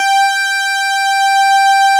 Added synth instrument
snes_synth_067.wav